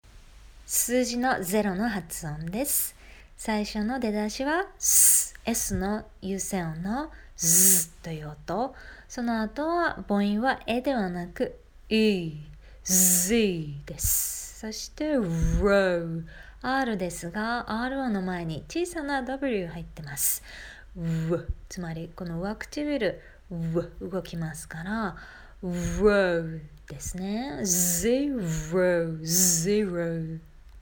zero は zíː + roʊという二つの音節から成り、
zero [zíːroʊ]
⇒ 発音とミニ解説は